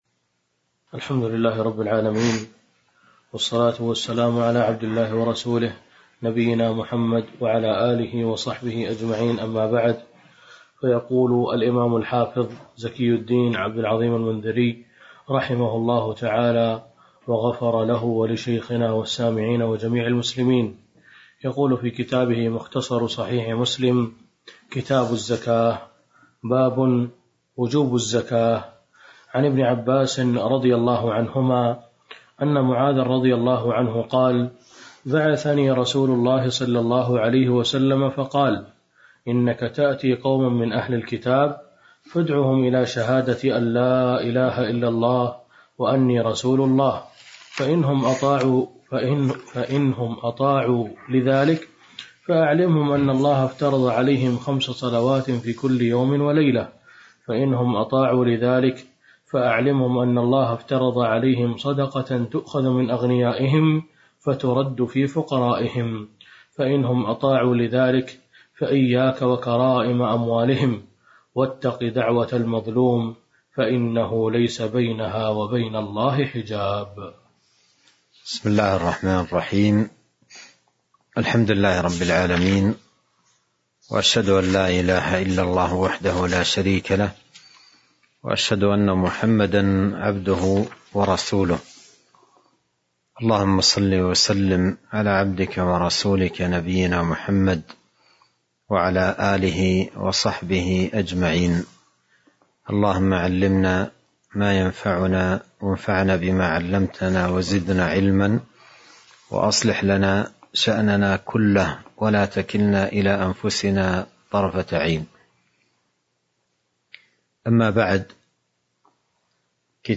تاريخ النشر ٦ رجب ١٤٤٢ هـ المكان: المسجد النبوي الشيخ